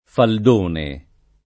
faldone [ fald 1 ne ]